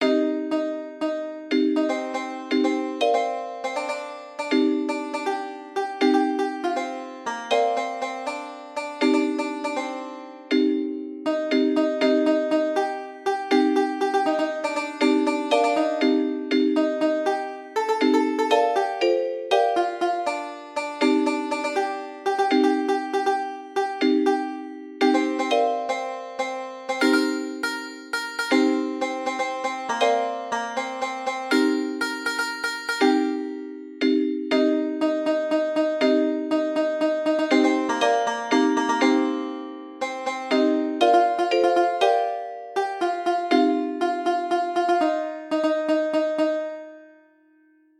Those that are just a piano are the MIDI processed through LMMS without any fiddling with the instruments.